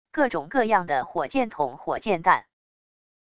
各种各样的火箭筒火箭弹wav音频